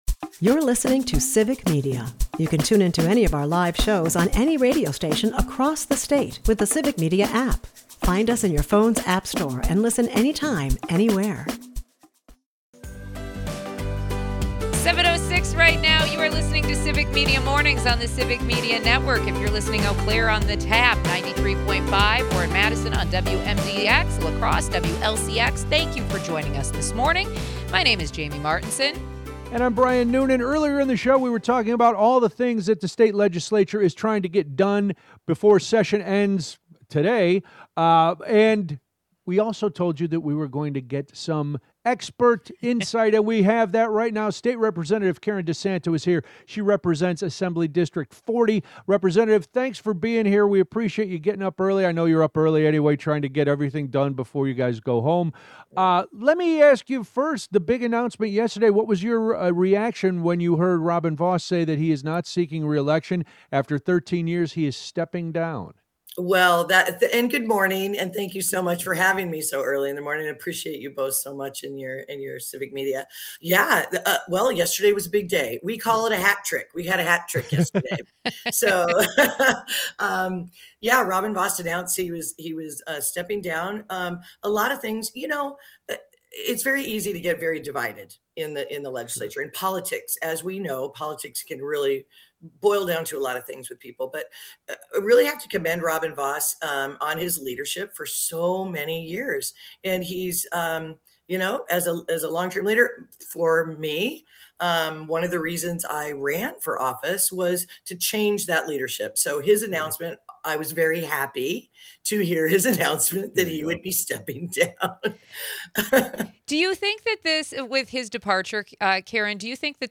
We start the hour talking with Rep. Karen DeSanto from Assembly District 40.